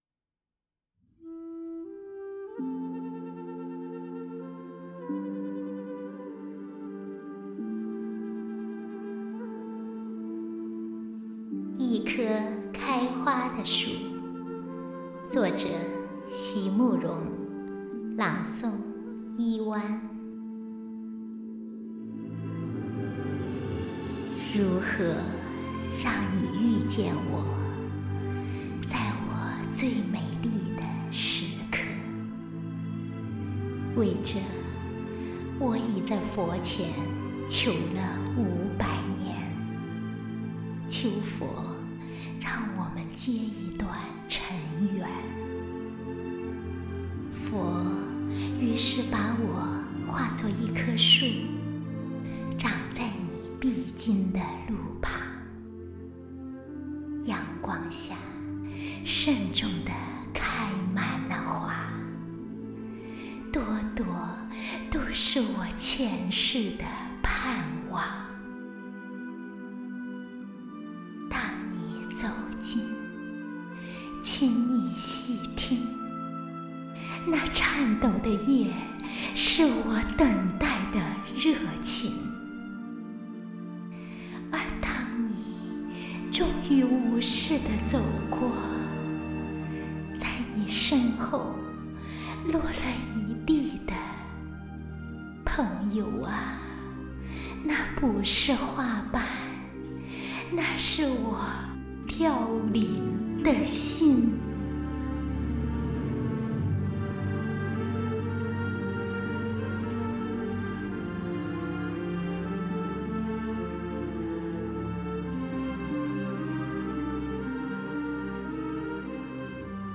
你這個叫喃喃自語可能更合適:-)